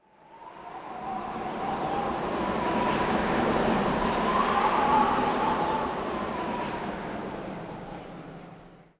windgust4.wav